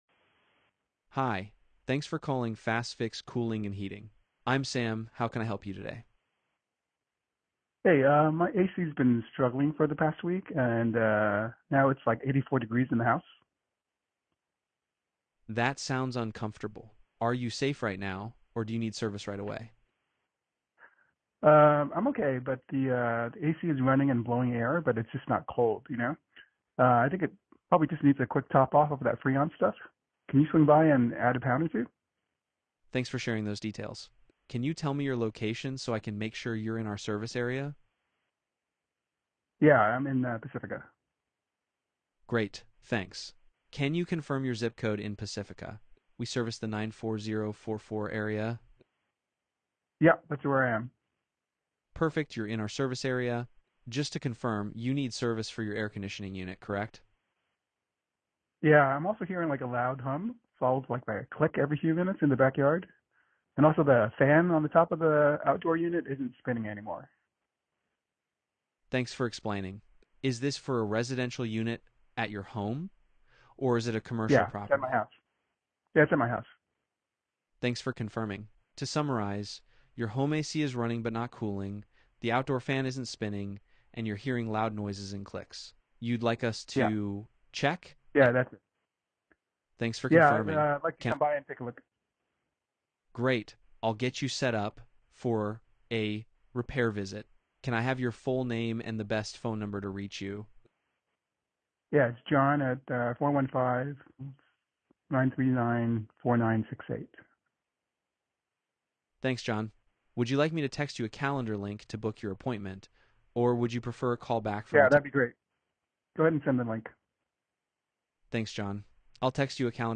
Listen to a live call with our Digital Dispatcher.
audio-hvac-call.m4a